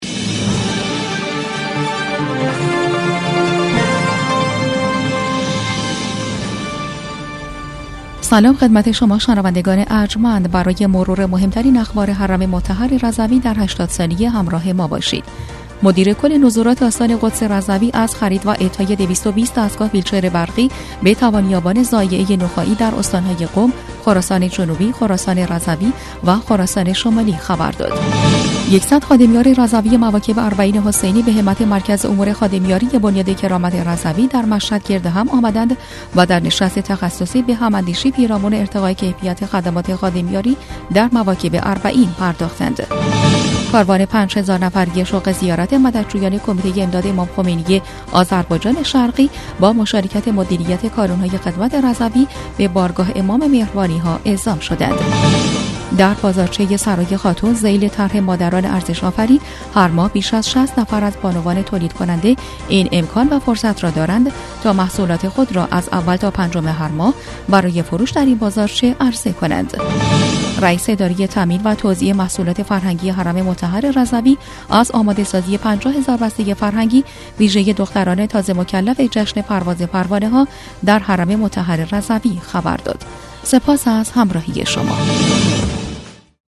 در این بسته خبری کوتاه، تازه‌ترین رویدادها، برنامه‌ها و خدمات آستان قدس رضوی را در کمترین زمان دنبال کنید؛ مروری سریع و فشرده بر خبرهای مهم حرم مطهر رضوی در هفته جاری.
برچسب ها: صوت رادیو بسته خبری رادیو رضوی